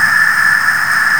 C VOX NOISE.wav